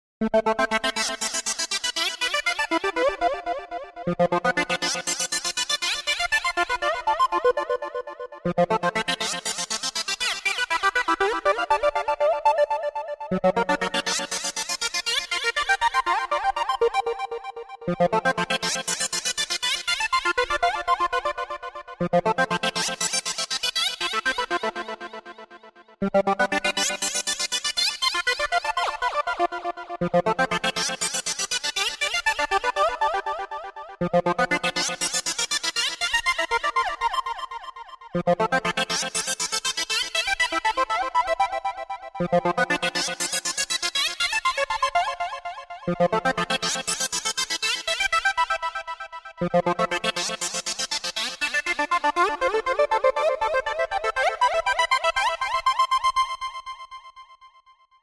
Live Performances, Semi-Industrial Life
I think the sound quality is just slightly better.